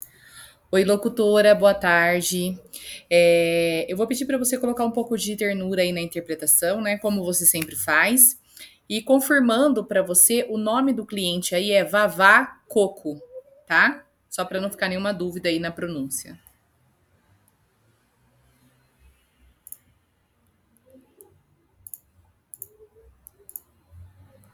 Produtor : Produzir com trilha natalina , colocar sininhos e risada do papai noel no final
Produtor : Produzir com trilha natalina .